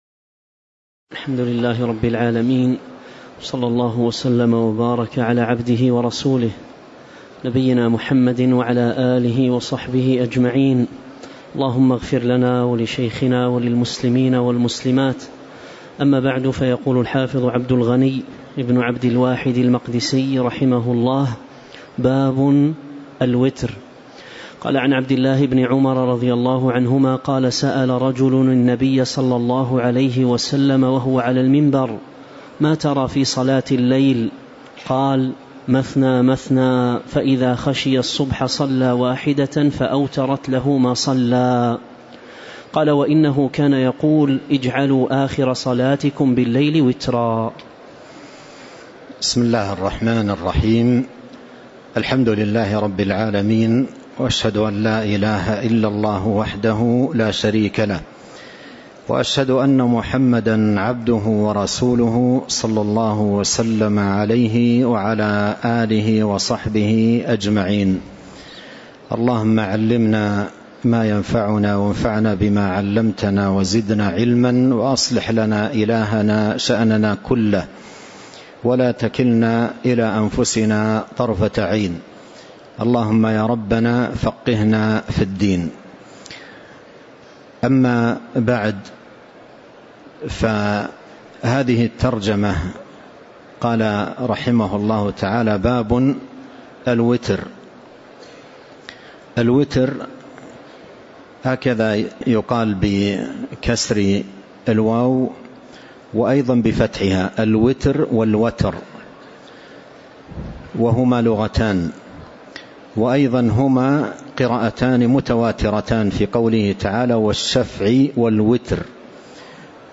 تاريخ النشر ١٠ جمادى الأولى ١٤٤٤ هـ المكان: المسجد النبوي الشيخ